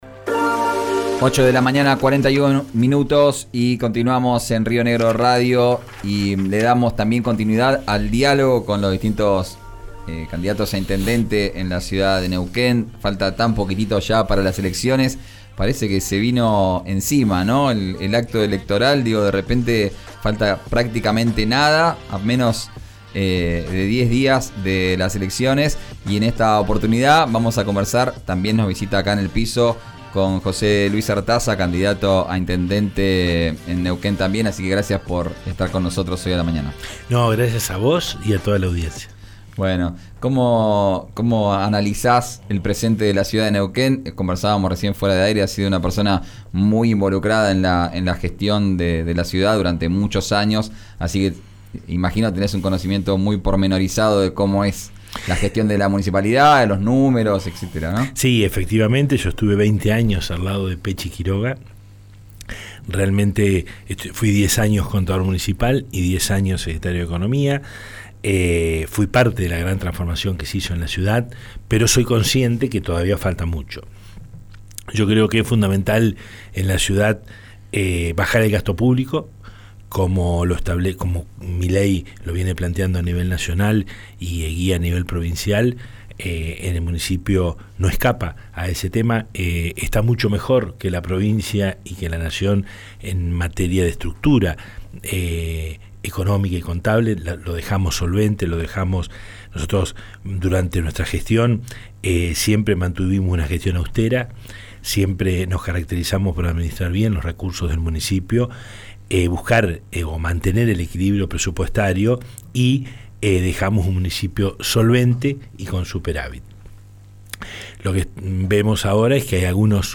Escuchá la entrevista completa en 'Vos al Aire'.